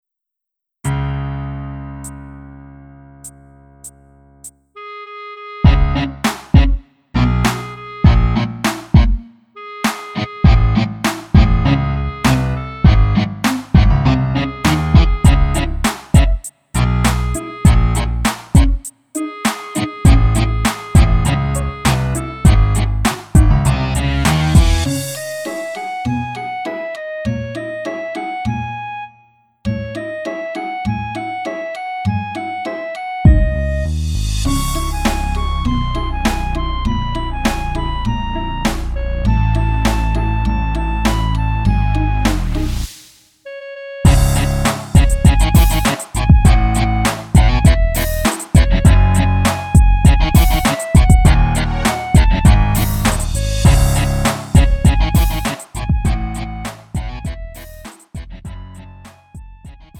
축가, 웨딩, 결혼식 MR. 원하는 MR 즉시 다운로드 가능.